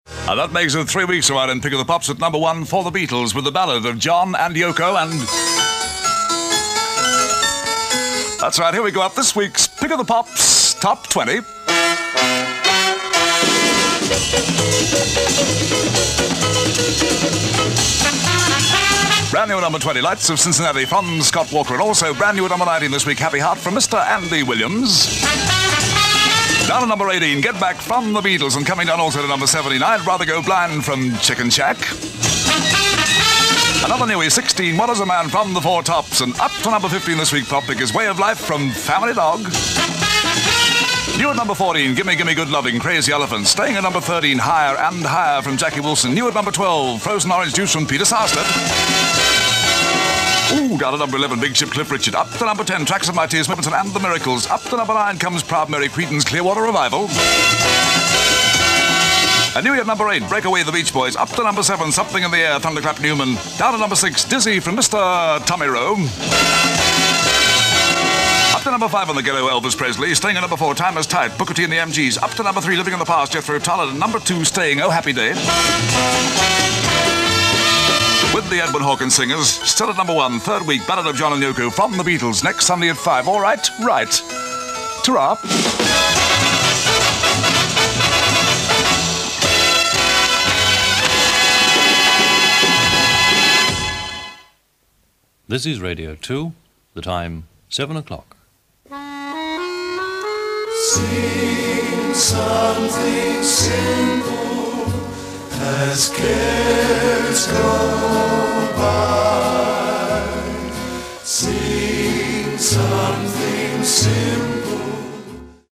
What a wonderful culture clash - with Alan Freeman at his peak hosting Pick of the Pops and hitting a Radio 2 junction.